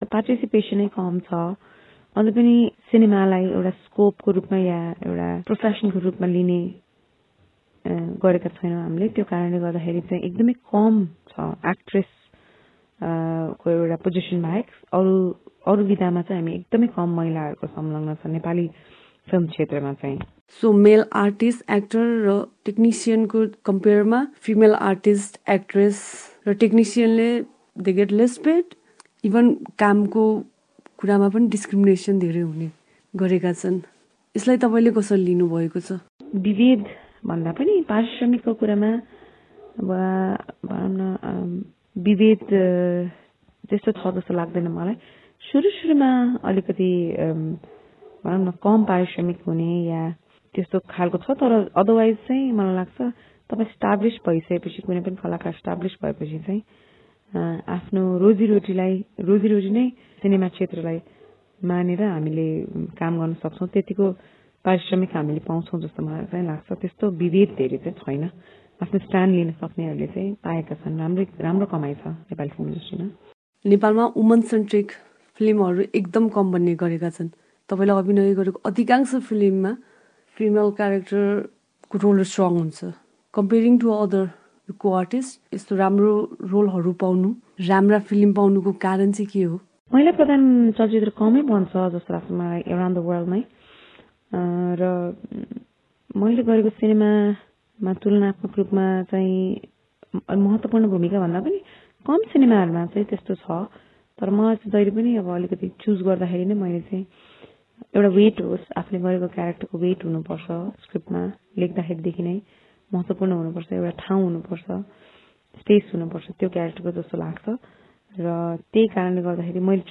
महिला हिंसाविरुद्धको १६ दिने अभियानअन्तर्गत उनीसँग एसबीएस नेपाली रेडियोले गरेको कुराकानी।